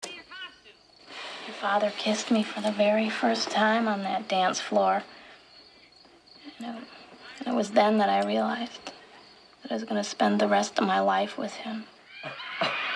Category: Movies   Right: Both Personal and Commercial
Tags: movie quote trivia pub quiz game time trivia